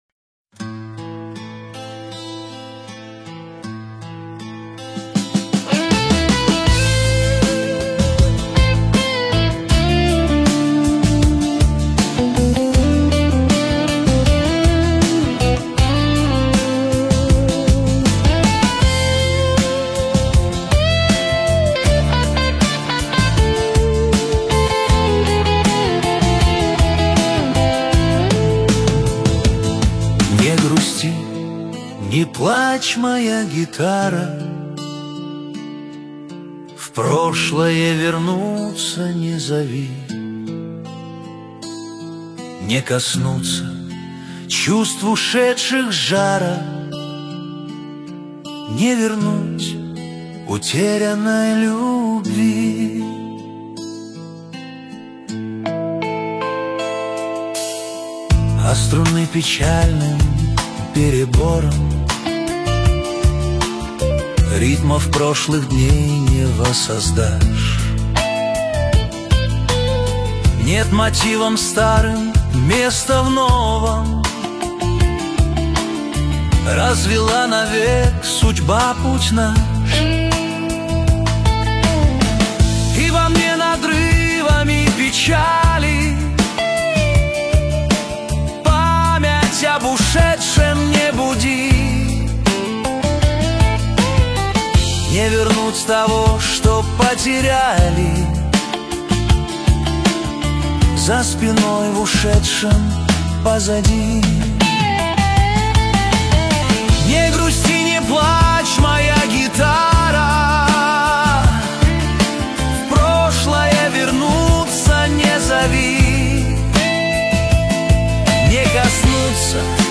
Гитара Песня Грусть